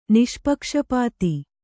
♪ niṣpakṣapāti